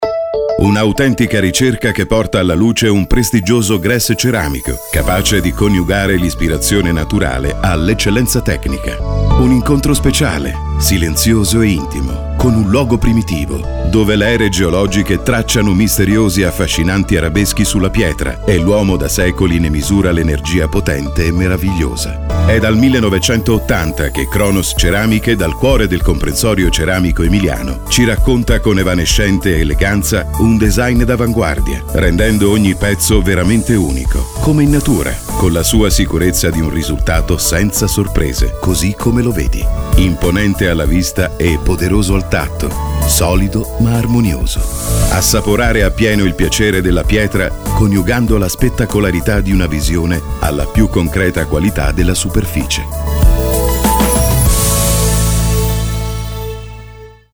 Lavoro anche in home studio (microfono Neumann Tlm 49, interfaccia Motu UltraLite-MK3 Hybrid, ambiente insonorizzato).
Sprechprobe: Industrie (Muttersprache):